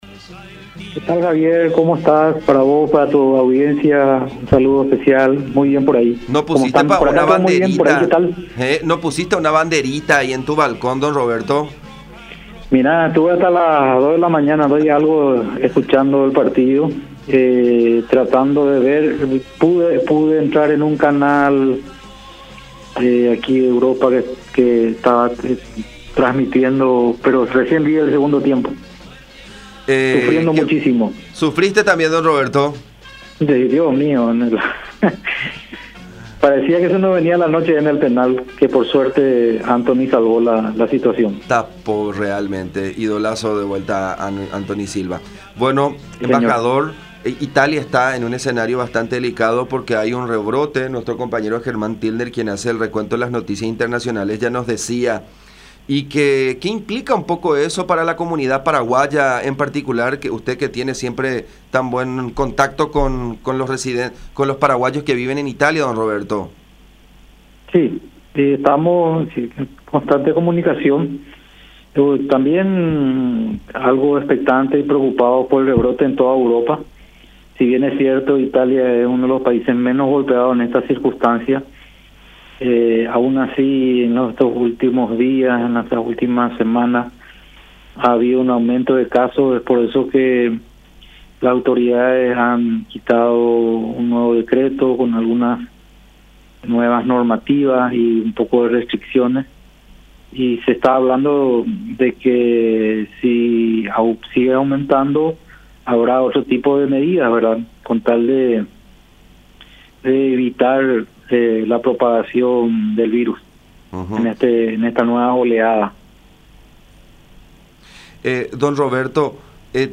“Hay un rebrote de contagios en toda Europa. Si bien Italia no está entre los más afectados, aún así en estos últimos días se ha producido un aumento de casos”, aseveró Melgarejo en conversación con La Unión.